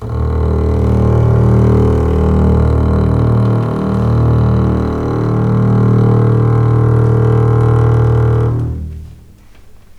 D#1 LEG MF R.wav